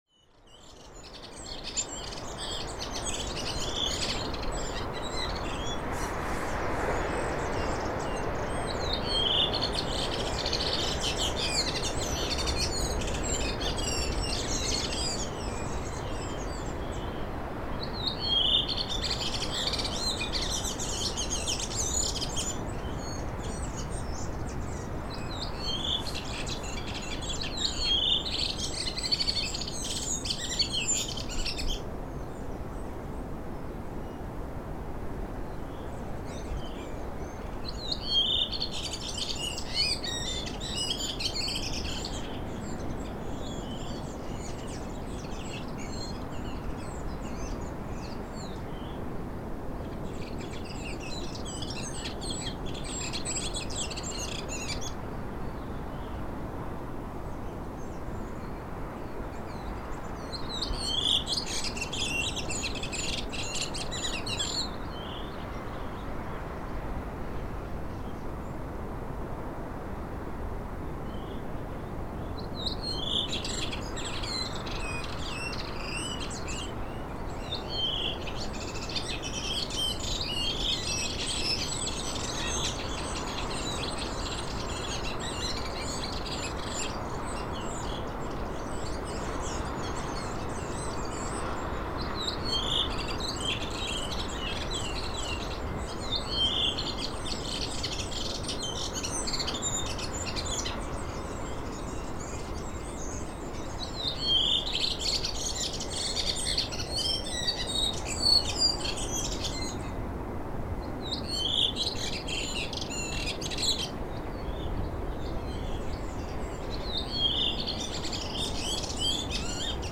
Gla�legur s�ngur �rastanna og m�fuglakli�ur � fjarska (hlj��rit fr� 9. ma� 2011).
A� sj�lfs�g�u bar �ar mest � s�ng sk�gar�rasta, en m�fugla heyr�um vi� � fjarska.
� d�ldinni �ar sem �g st�� var stafalogn og �v� var unnt a� stilla Olympus LS-11 � mesta styrk. � fjarska heyrist �gir gamli g�la vi� gamla Fr�n.
_rastasongur_og_mofuglar_i_fjarska.mp3